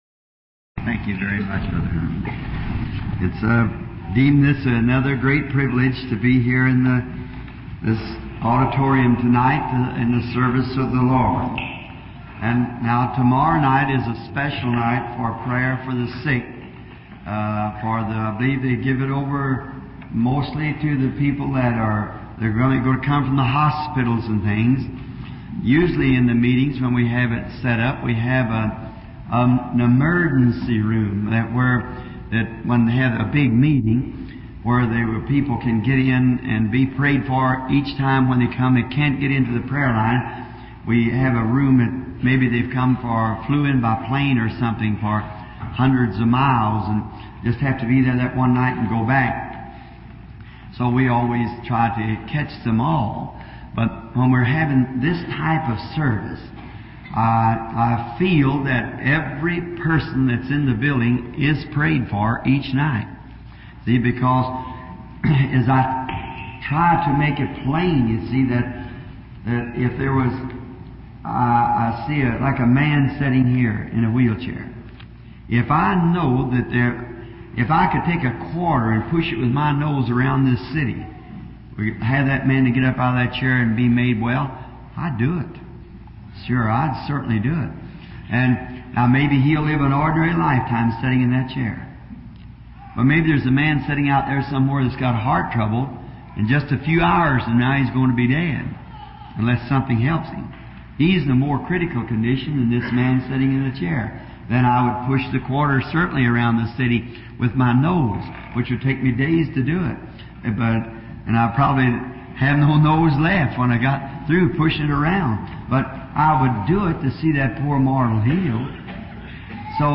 die ca. 1200 aufgezeichneten Predigten